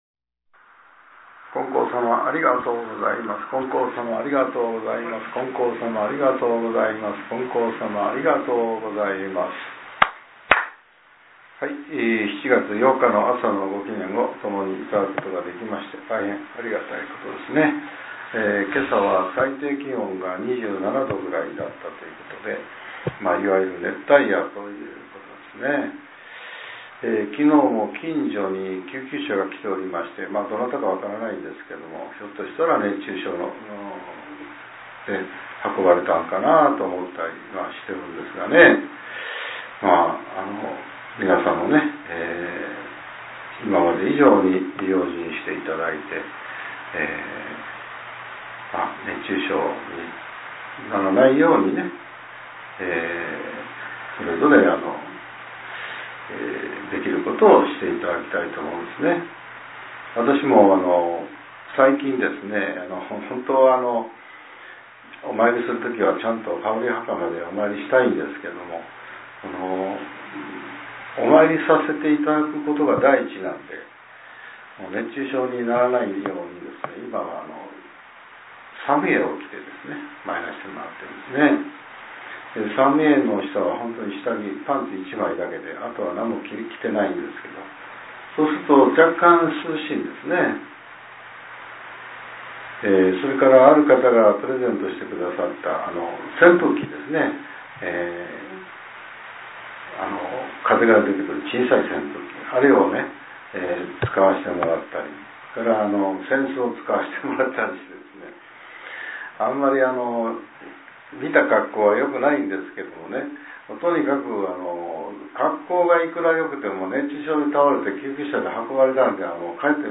令和７年７月８日（朝）のお話が、音声ブログとして更新させれています。